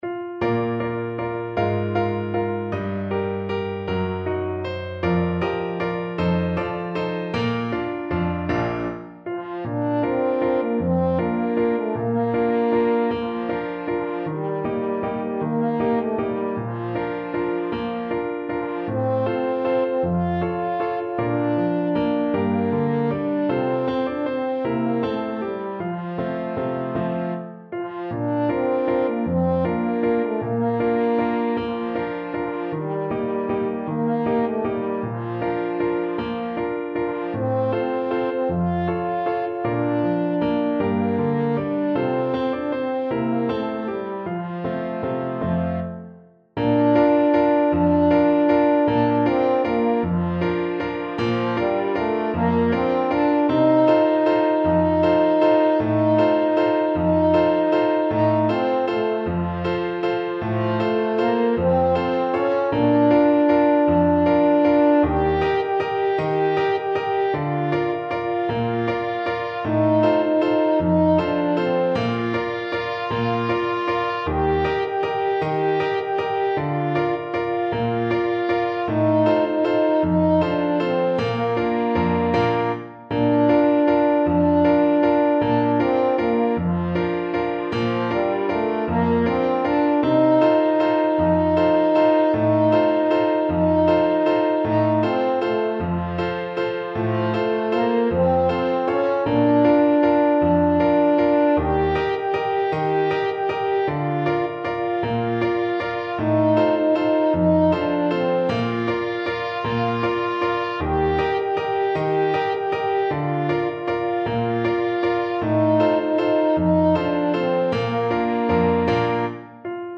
3/4 (View more 3/4 Music)
One in a bar .=c.52
F4-G5
Classical (View more Classical French Horn Music)